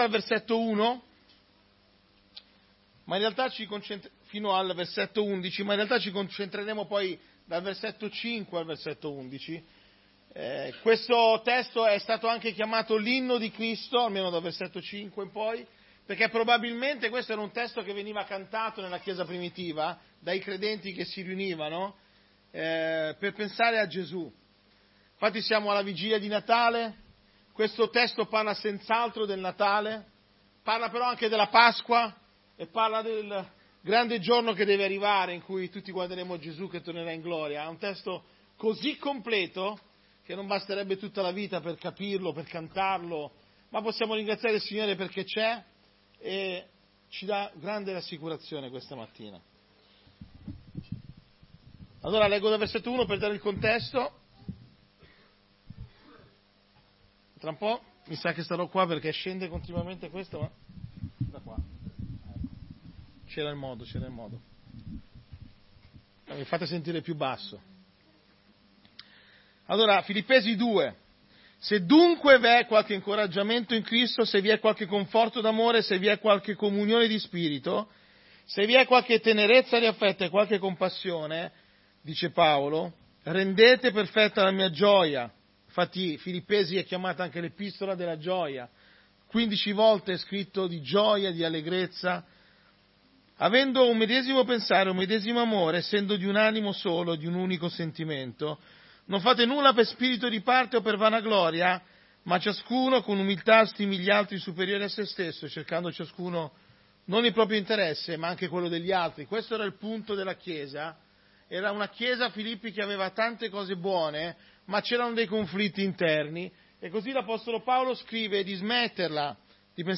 Tutti i sermoni